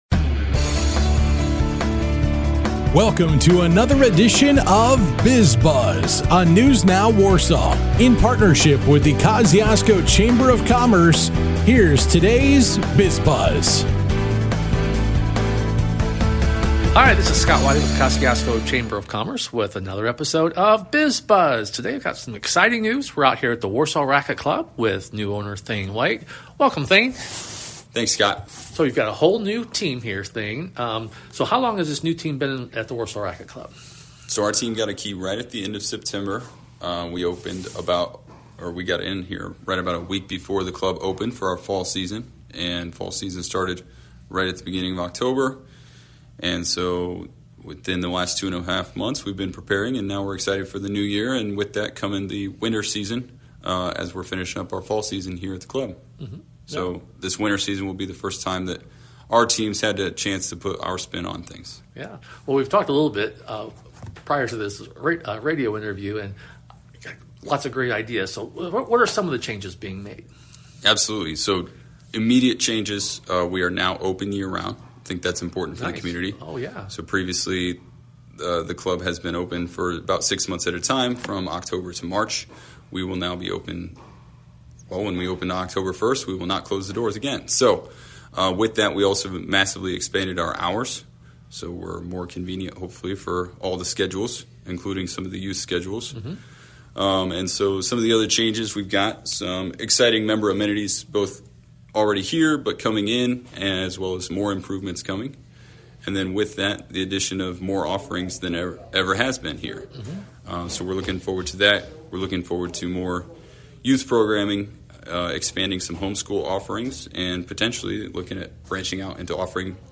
A chat with Warsaw Racquet Club and TD Cleaning